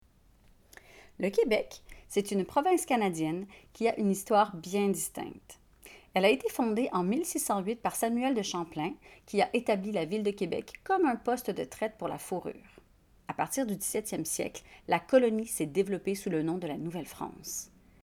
12 - 94 ans - Mezzo-soprano